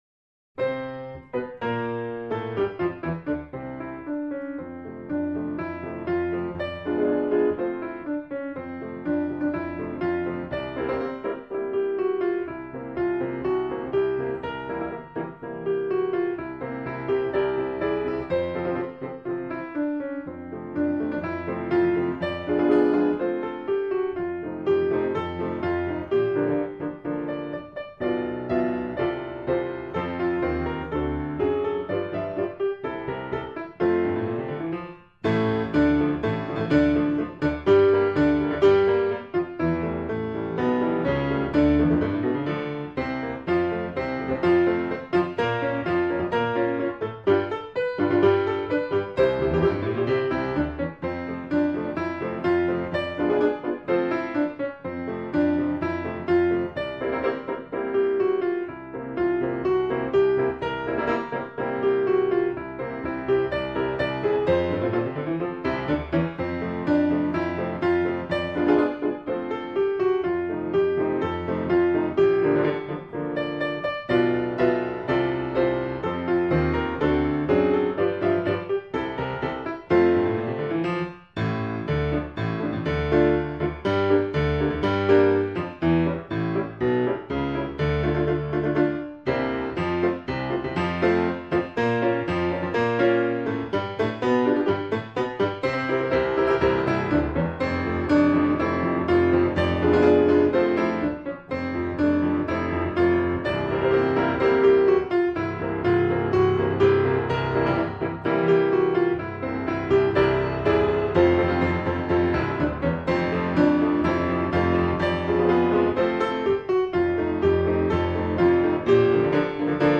คำสำคัญ : เปียโน, เพลงพระราชนิพนธ์, มาร์ชราชนาวิกโยธิน